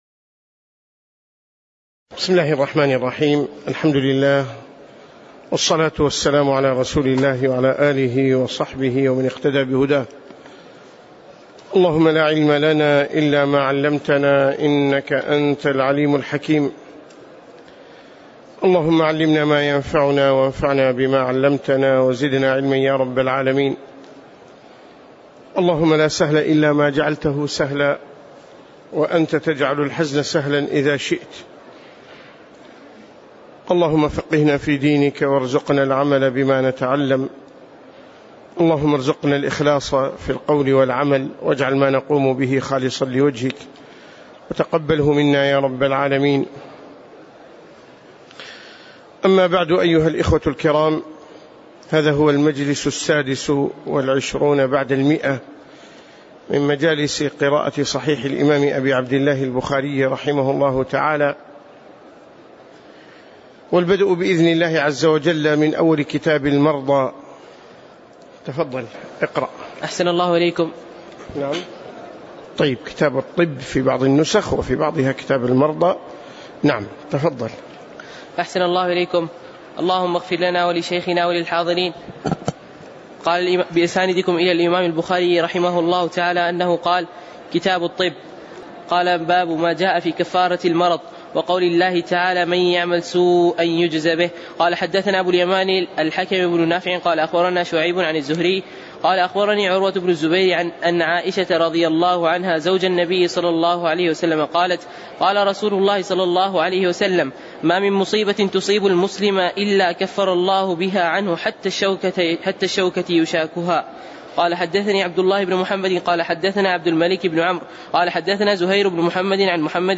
تاريخ النشر ٢٨ شعبان ١٤٣٨ هـ المكان: المسجد النبوي الشيخ